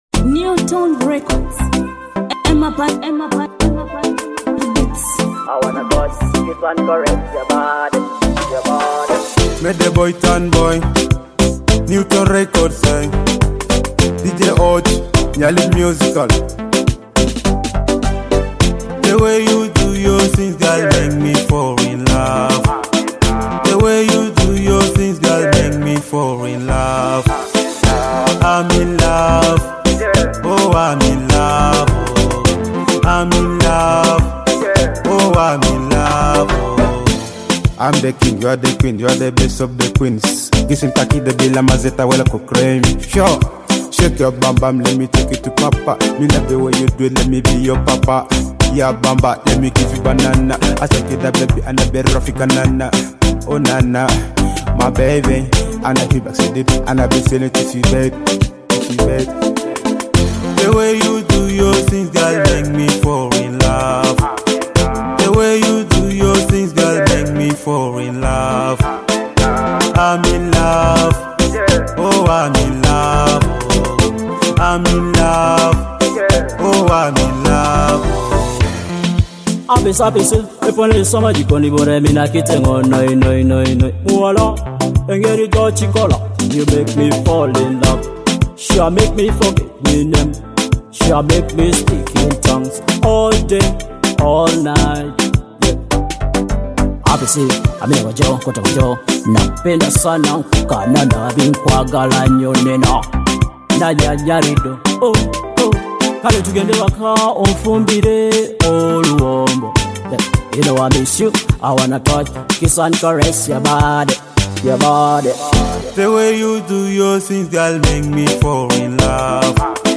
a fresh Ugandan love dancehall track in English